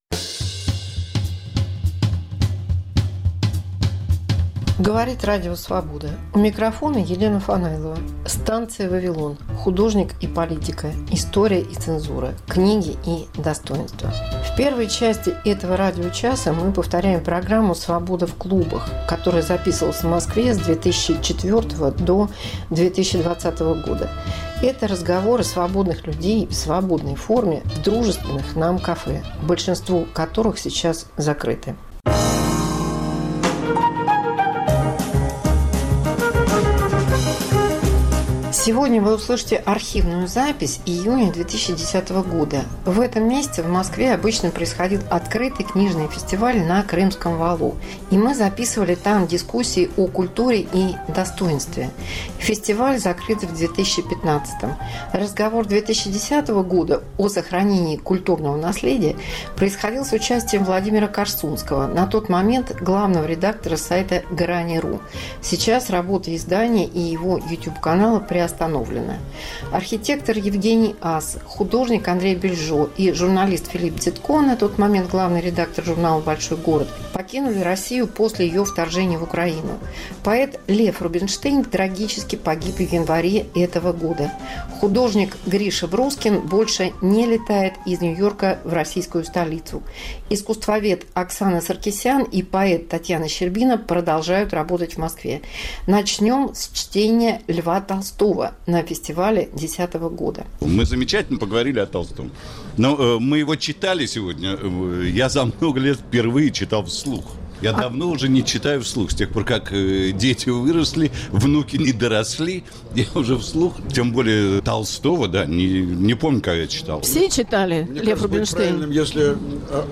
1. Дискуссия на Московском открытом книжном фестивале, архив 2010.